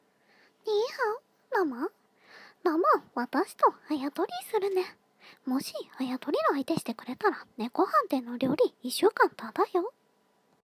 シャンプーの声真似③